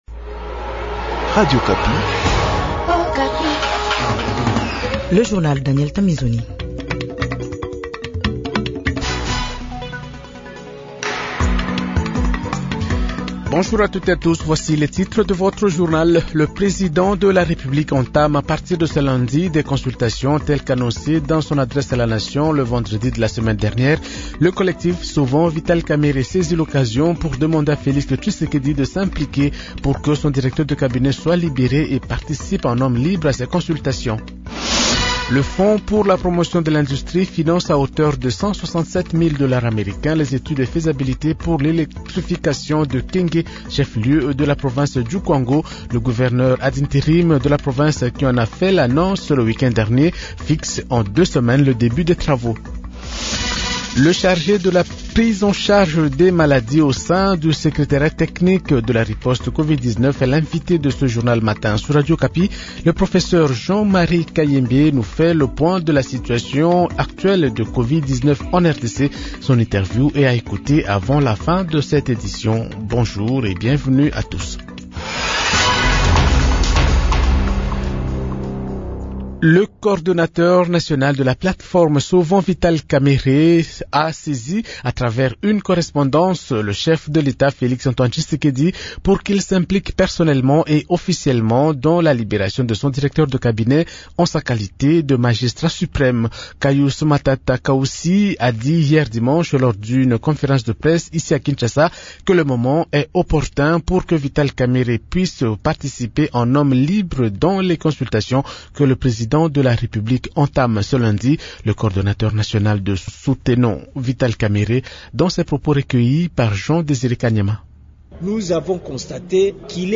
Journal Francais Matin 6h00